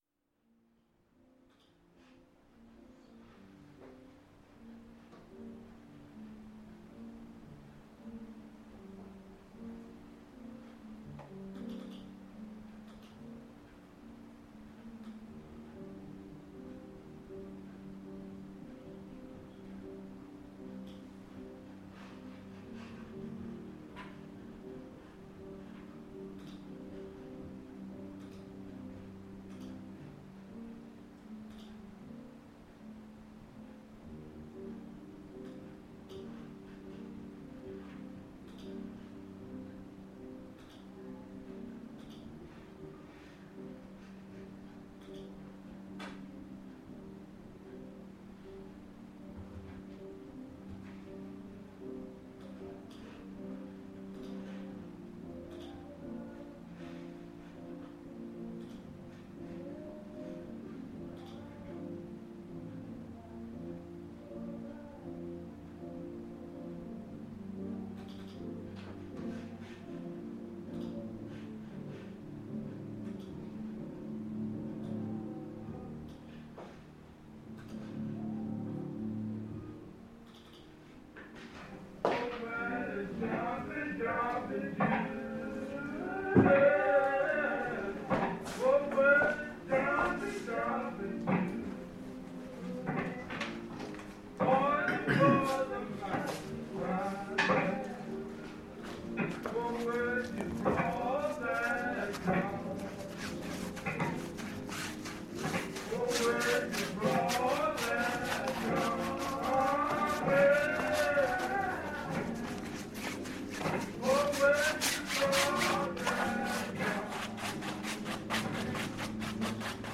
Reimagined version